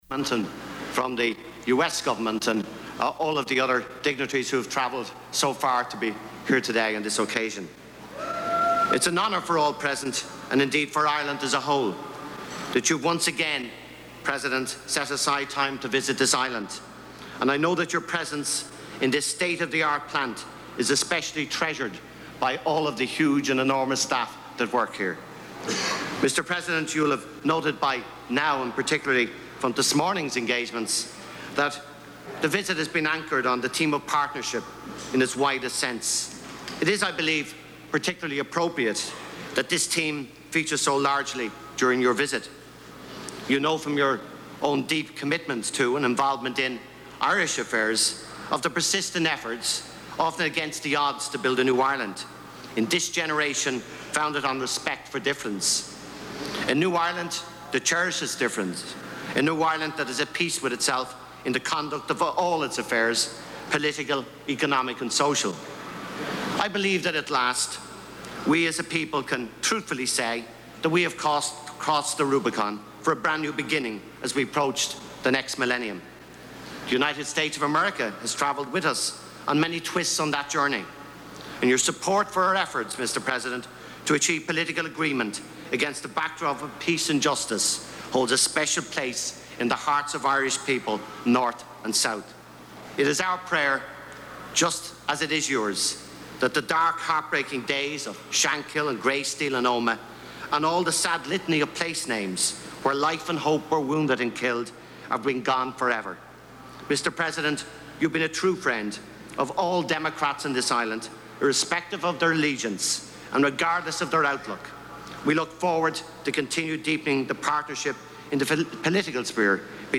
President Clinton, in Santry, Ireland, at the Gateway Electronics plant, talks about Irish-American unity
U.S. President Bill Clinton talks about Irish-American unity while visiting the Gateway Electronics plant in Santry, Ireland.
Broadcast on CNN-TV, News Special, September 4, 1998.